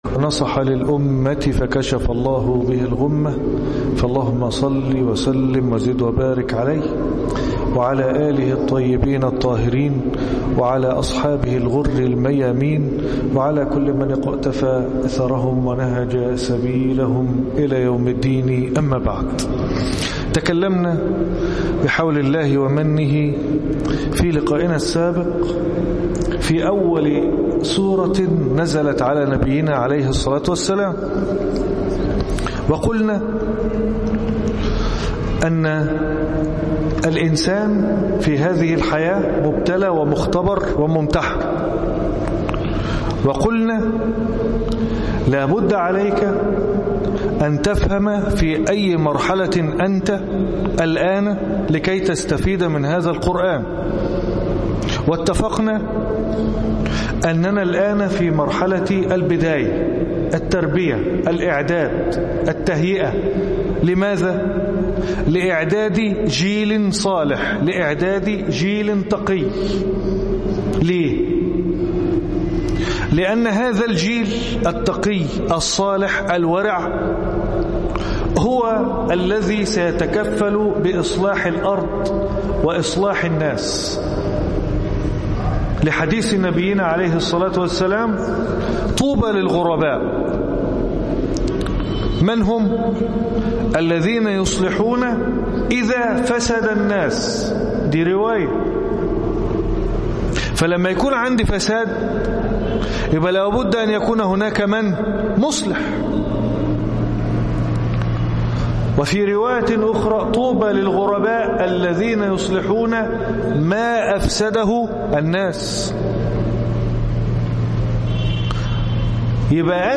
الدرس السادس عشر ( كيف تتلذذ بقراءة القرآن